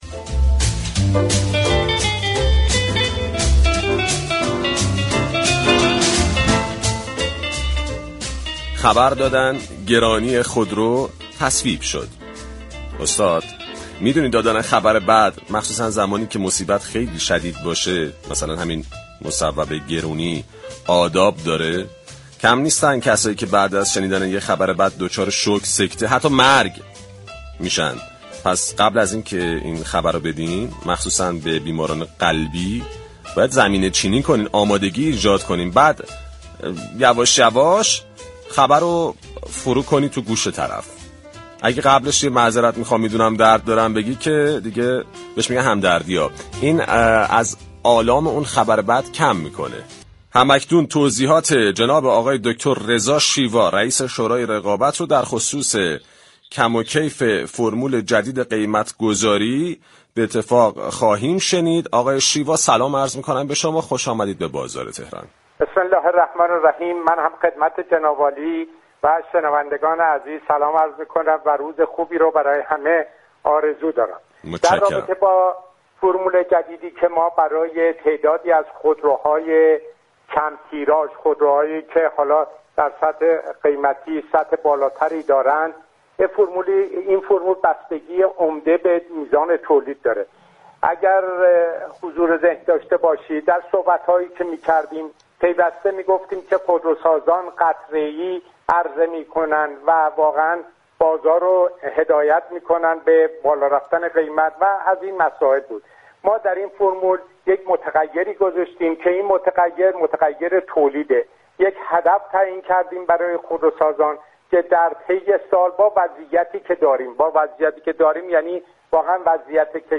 رضا شیوا در گفتگو با برنامه بازار تهران درخصوص كم و كیف فرمول جدید قیمت گذاری خودرو اظهار داشت: فرمول جدید برای 18 خودرو كه گرانتر و كم تیراژتر از خودروهای دیگر هستند توسط شورای رقابت ارائه شده است.